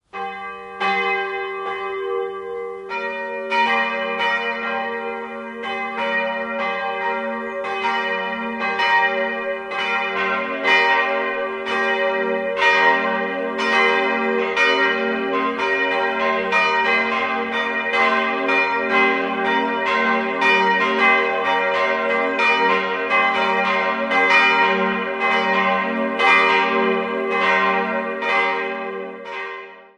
3-stimmiges TeDeum-Geläute: g'-b'-c'' Die große Glocke (Gussjahr 1951) und die mittlere Glocke (Gussjahr 1956) wurden von Rudolf Perner gegossen.